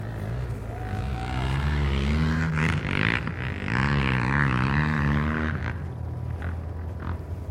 越野车 " 摩托车 越野车 越野车快速通过
描述：摩托车越野车摩托车越野赛快速通过